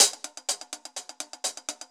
Index of /musicradar/ultimate-hihat-samples/125bpm
UHH_AcoustiHatC_125-05.wav